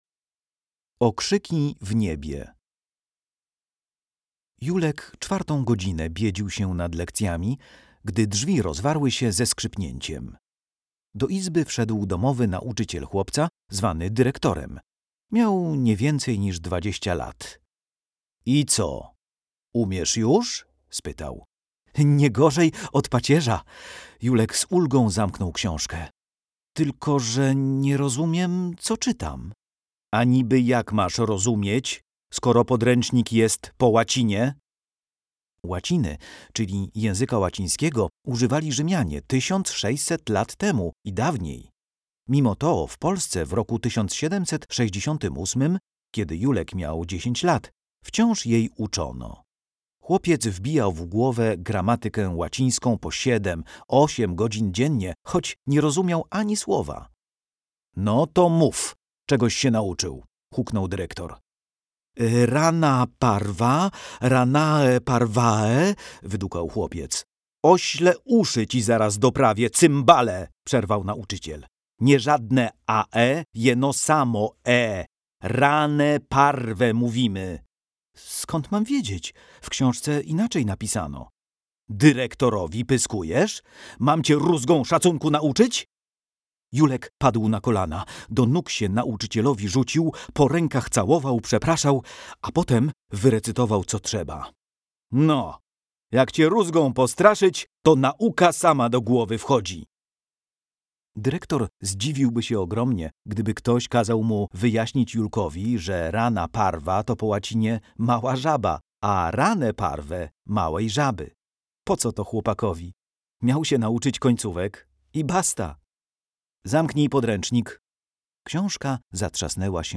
Słuchowiska. Klasa 6